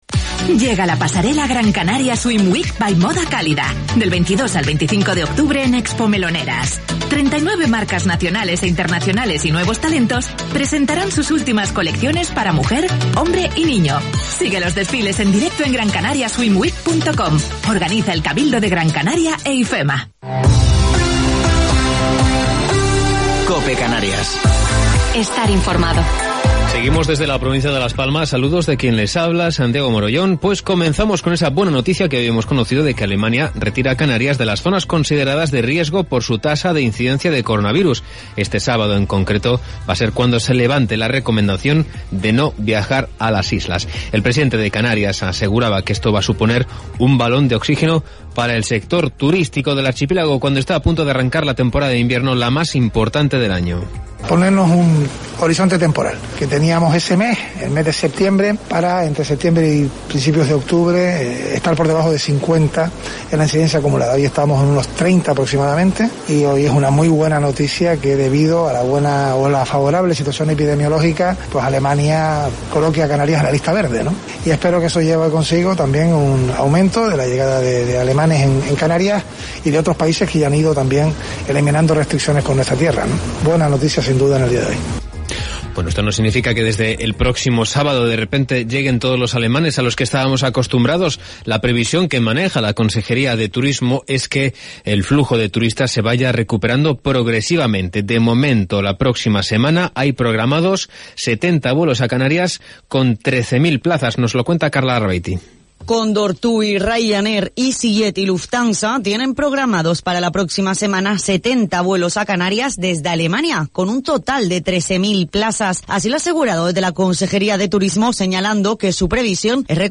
Informativo local 22 de Octubre del 2020